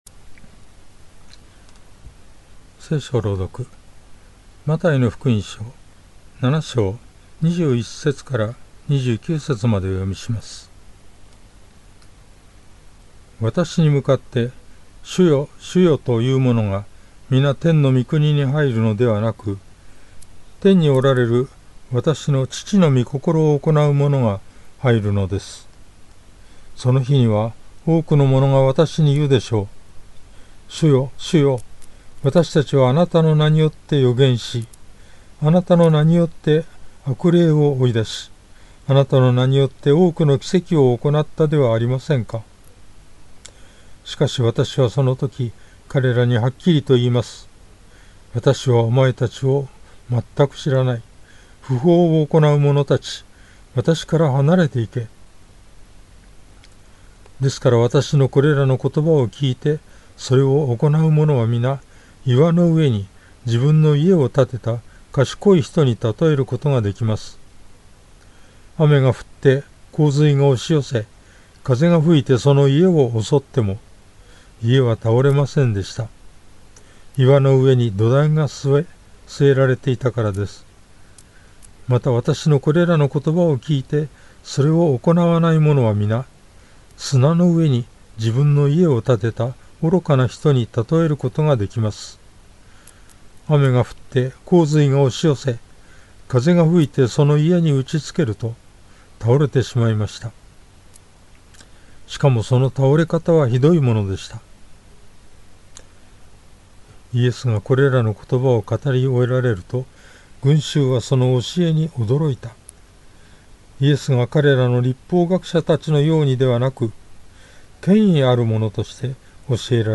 BibleReading_Math7.21-29.mp3